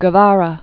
(gə-värə, gĕ-värä), Ernesto Known as "Che." 1928-1967.